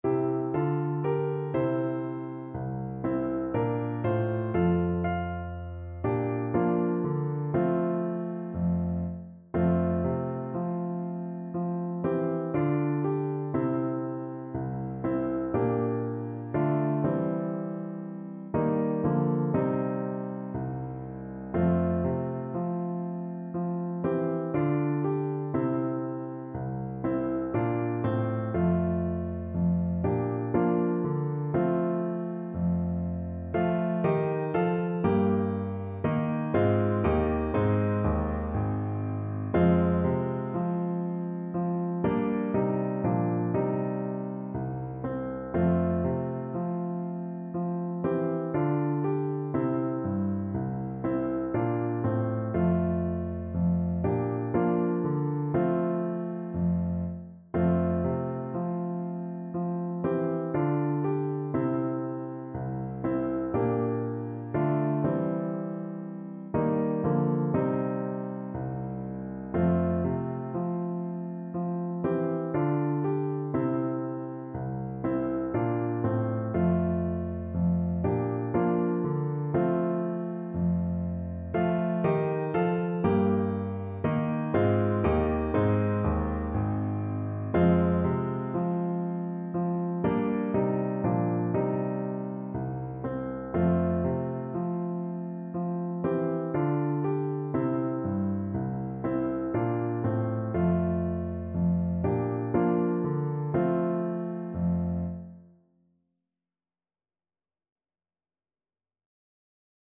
Play (or use space bar on your keyboard) Pause Music Playalong - Piano Accompaniment Playalong Band Accompaniment not yet available transpose reset tempo print settings full screen
Voice
F major (Sounding Pitch) (View more F major Music for Voice )
3/4 (View more 3/4 Music)
Slow, expressive =c.60
Classical (View more Classical Voice Music)